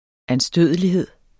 Udtale [ anˈsdøˀðəliˌheðˀ ]